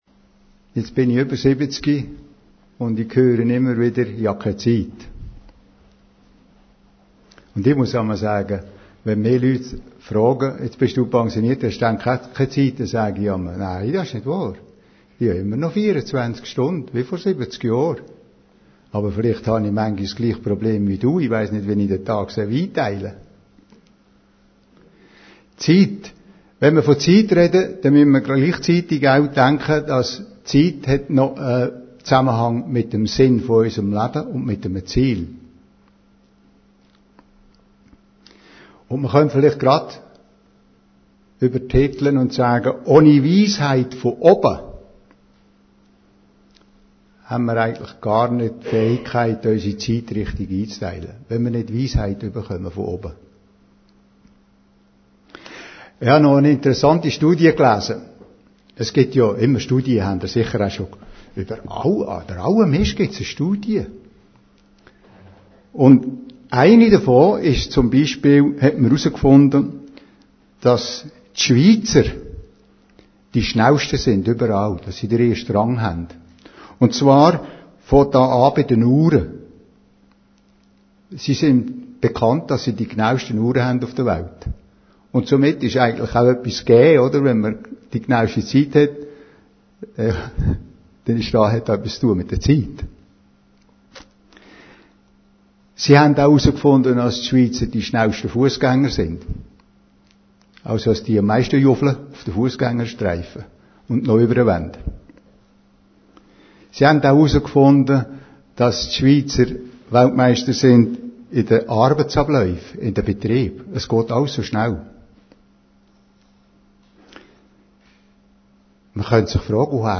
Predigten Heilsarmee Aargau Süd – Meine Zeit